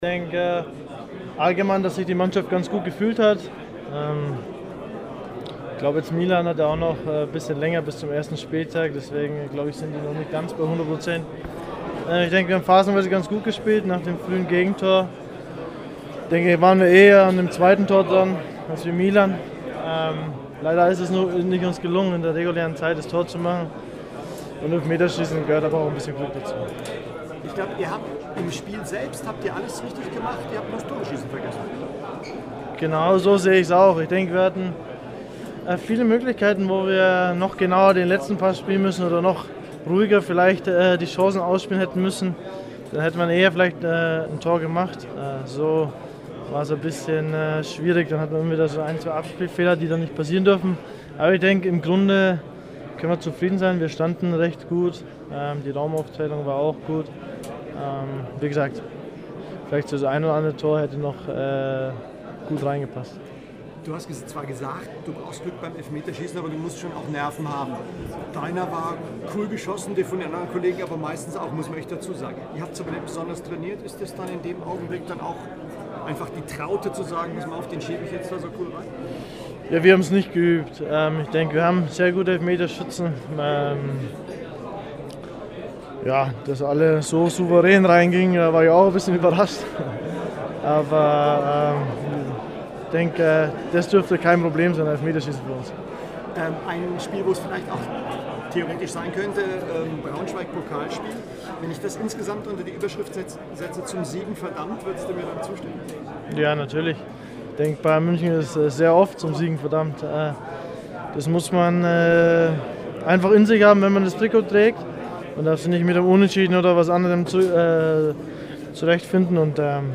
O-Ton-Paket Bastian Schweinsteiger